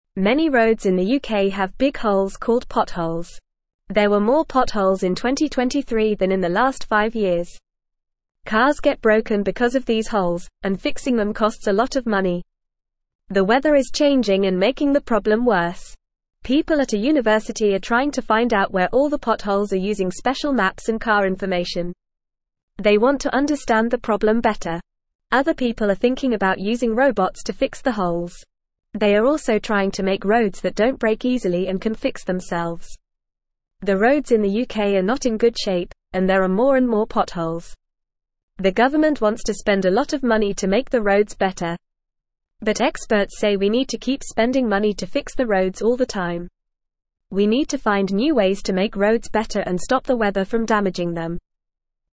Fast
English-Newsroom-Lower-Intermediate-FAST-Reading-UK-Roads-with-Holes-Smart-People-Fixing-Them.mp3